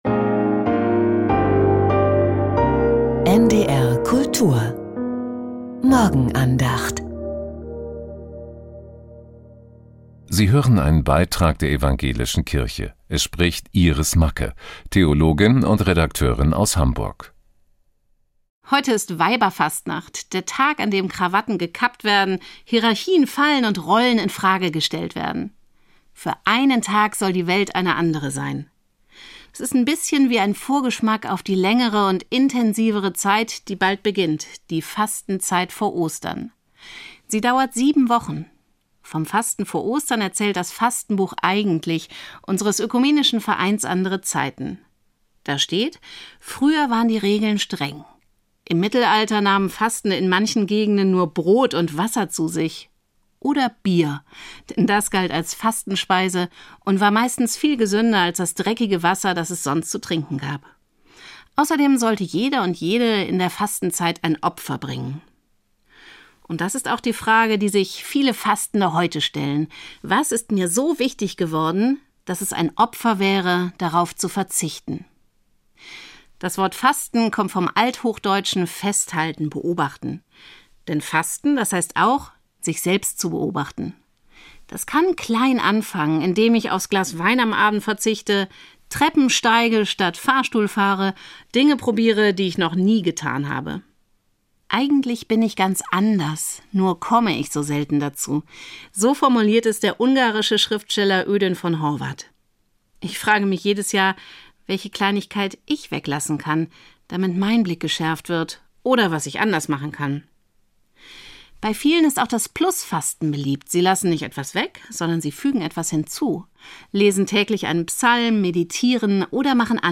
Heute ist Weiberfastnacht. ~ Die Morgenandacht bei NDR Kultur Podcast